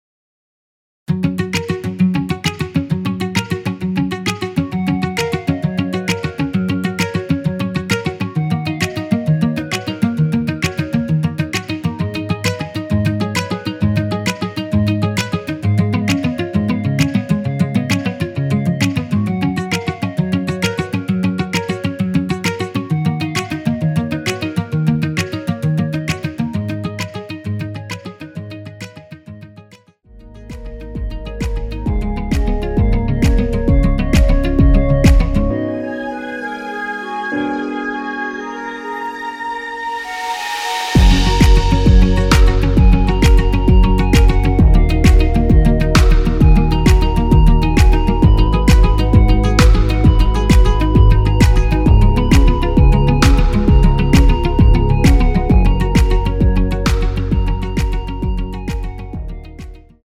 원키에서(+4)올린 멜로디 포함된 MR입니다.(미리듣기 확인)
F#
앞부분30초, 뒷부분30초씩 편집해서 올려 드리고 있습니다.
중간에 음이 끈어지고 다시 나오는 이유는